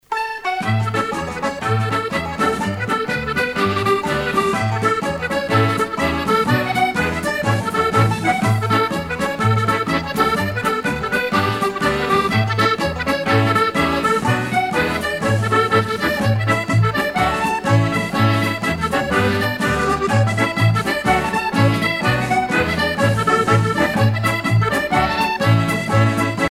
danse : jig
Pièce musicale éditée